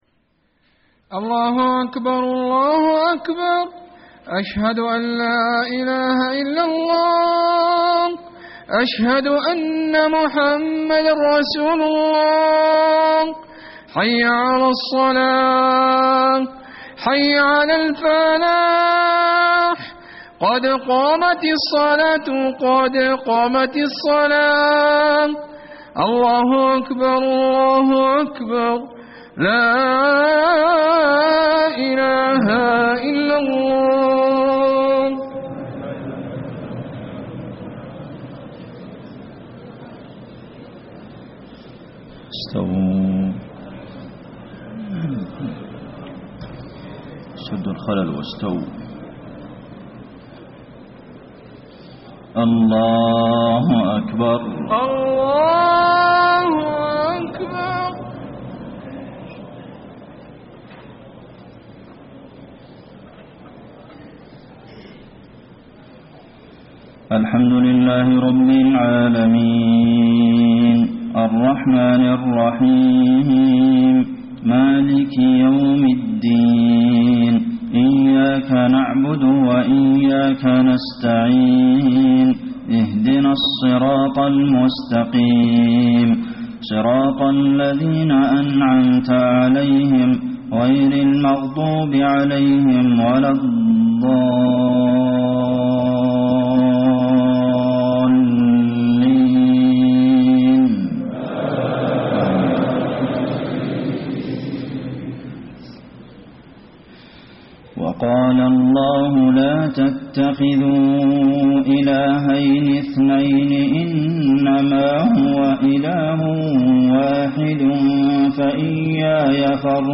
صلاة المغرب 9-8-1434 من سورة النحل > 1434 🕌 > الفروض - تلاوات الحرمين